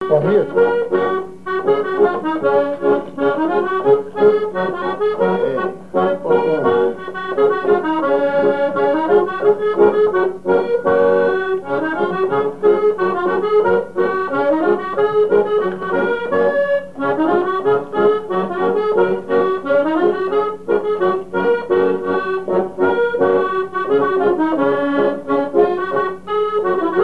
danse : mazurka
Pièce musicale inédite